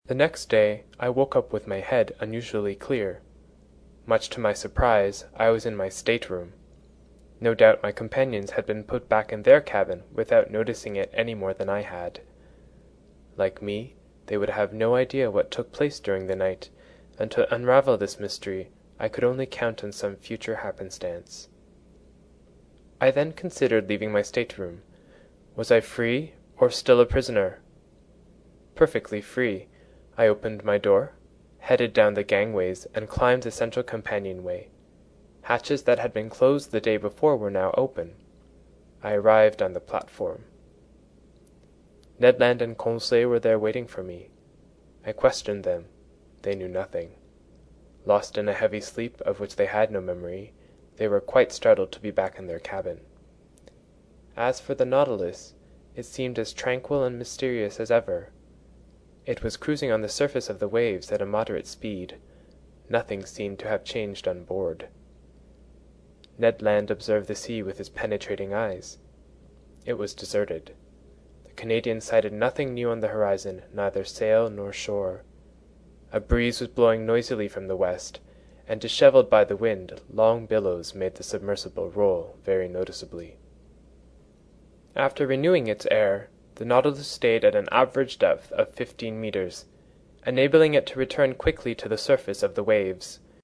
在线英语听力室英语听书《海底两万里》第304期 第23章 珊瑚王国(1)的听力文件下载,《海底两万里》中英双语有声读物附MP3下载